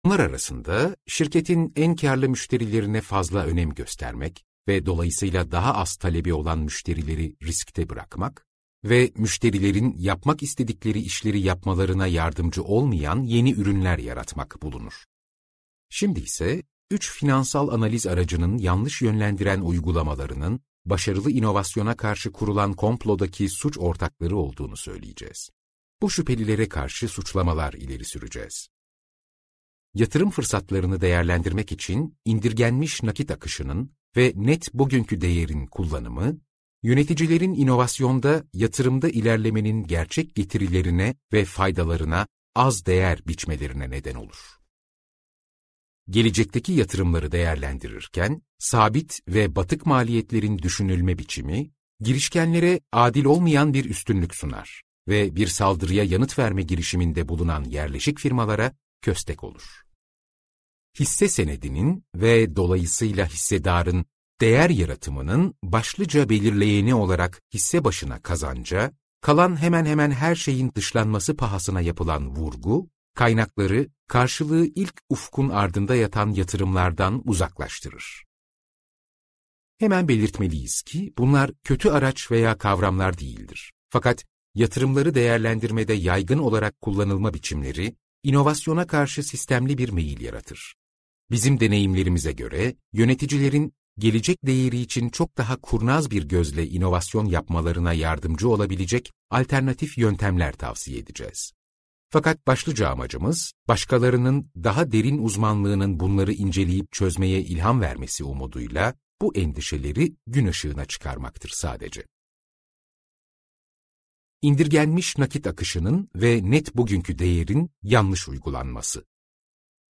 İnovasyon Katilleri - Seslenen Kitap